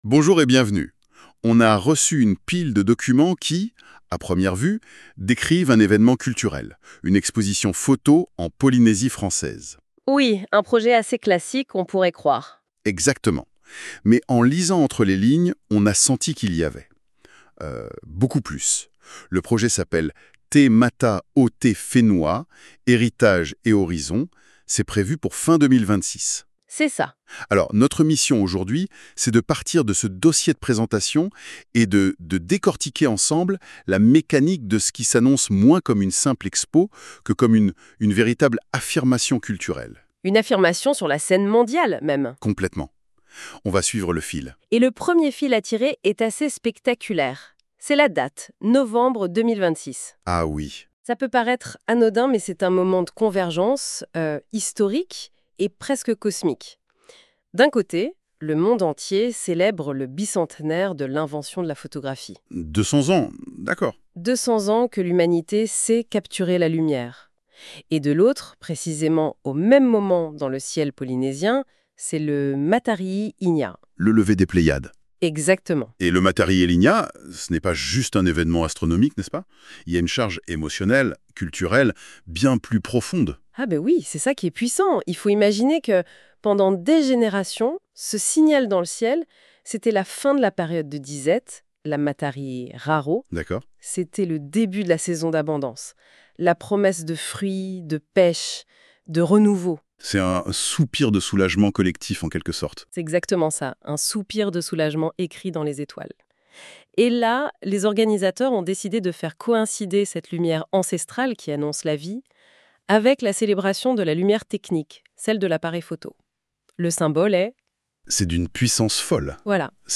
Conversation autour de la portée culturelle du projet TE MATA O TE FENUA, de la question des regards (endogènes/exogènes), du bicentenaire de la photographie et du Matari’i i ni’a, et de la manière dont une exposition peut devenir un acte d’affirmation plutôt qu’une vitrine.